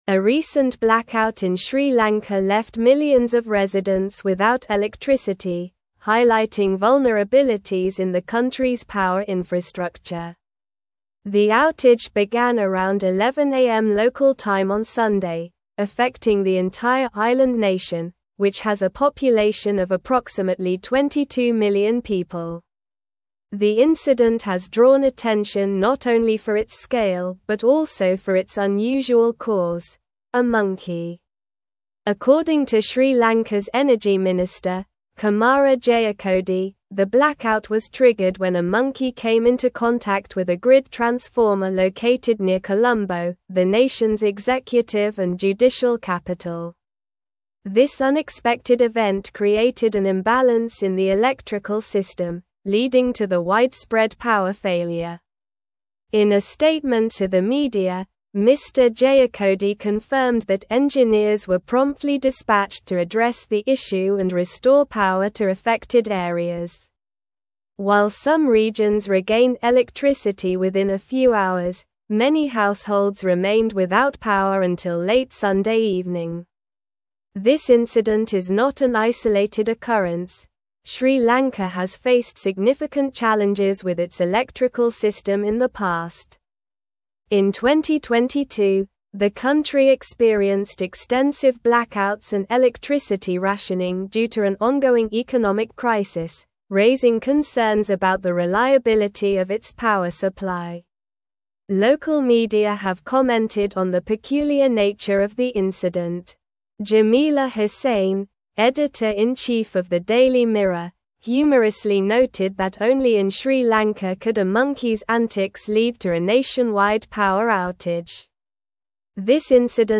Slow
English-Newsroom-Advanced-SLOW-Reading-Monkey-Causes-Nationwide-Blackout-in-Sri-Lanka.mp3